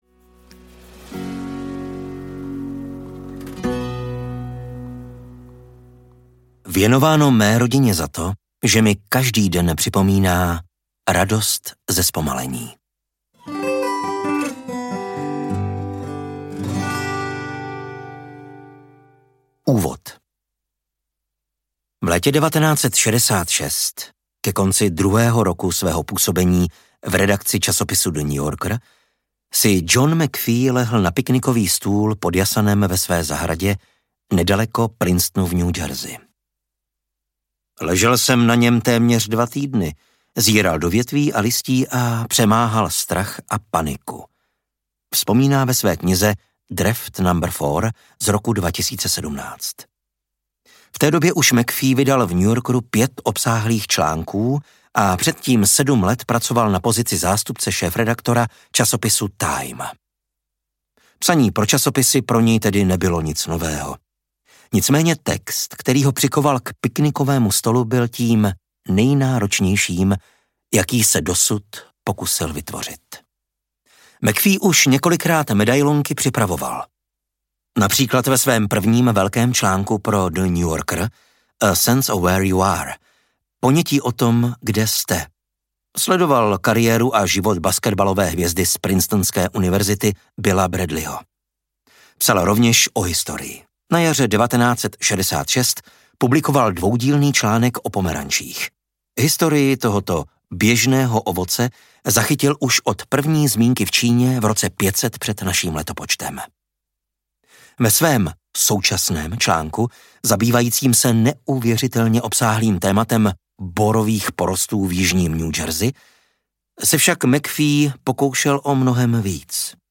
Pomalá produktivita audiokniha
Ukázka z knihy